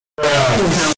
Earrape Bruh Earrape Sound Effect Free Download
Earrape Bruh Earrape